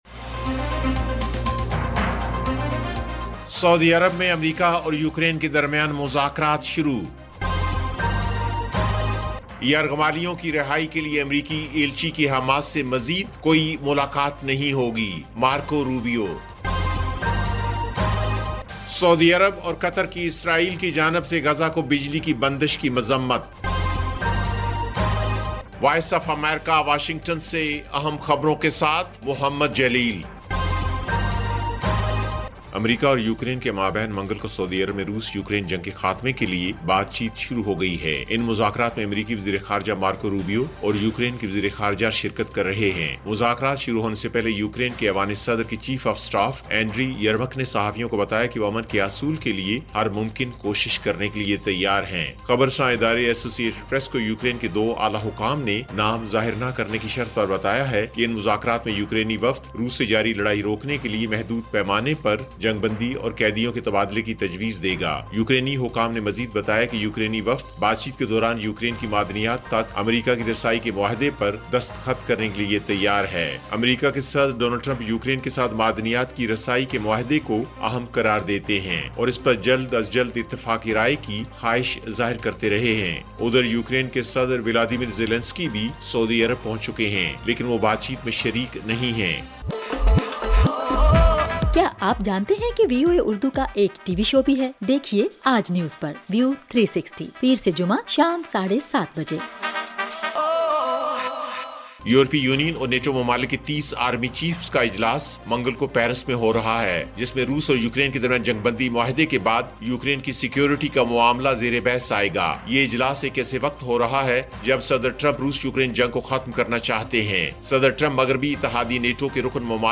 ایف ایم ریڈیو نیوز بلیٹن: شام 6 بجے